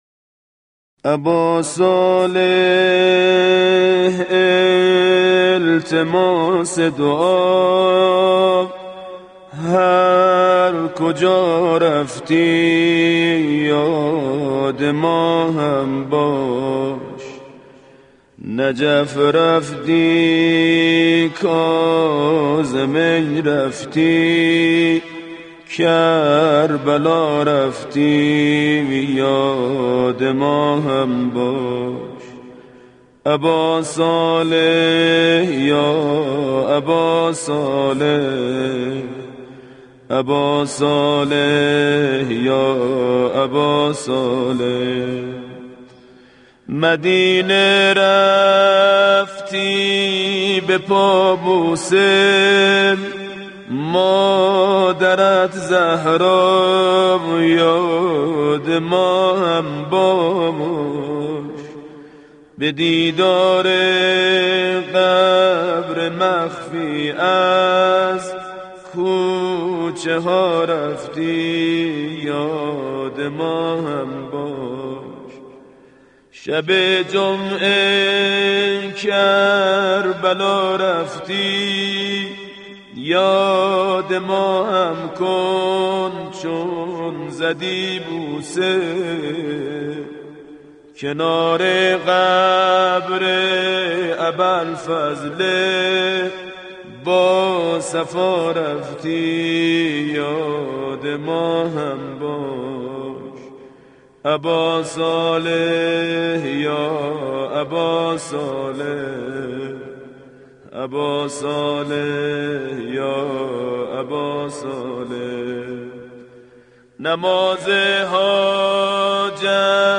این مداحی که امروز برای شما در اینجا قرار دادم مداحی با موضوع امام زمان (عج) است که بسیار زیبا می باشد .
من خیلی این نوحه رو دوست دارم ...